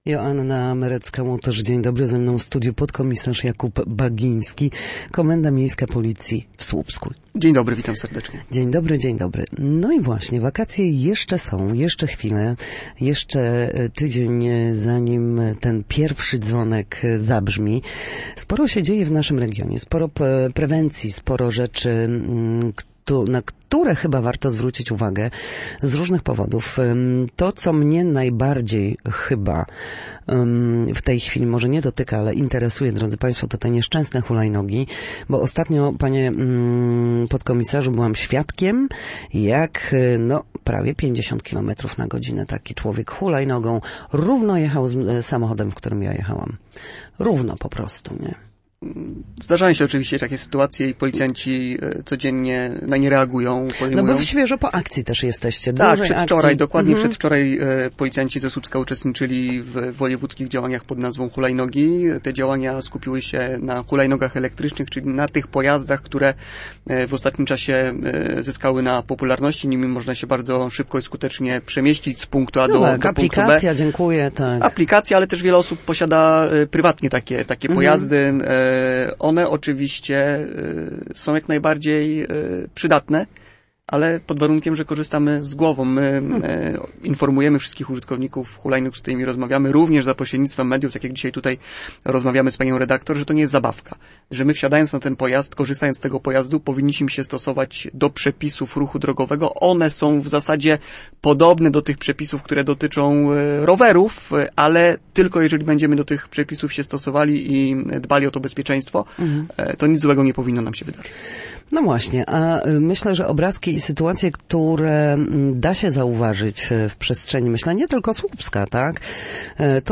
Gościem Studia Słupsk